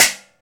Index of /90_sSampleCDs/Roland - Rhythm Section/PRC_Trash+Kitch/PRC_Trash menu